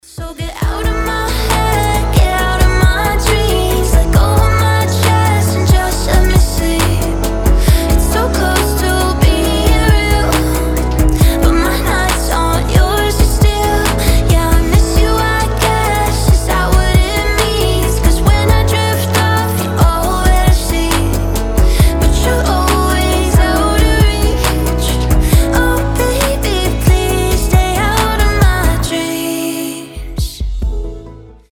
мелодичные
красивый женский голос
нежные